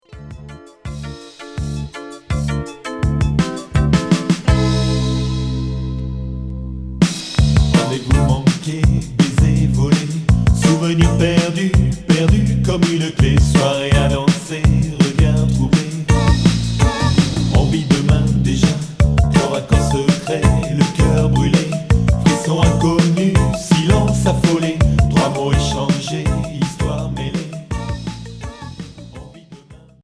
Minimal synth Unique 45t retour à l'accueil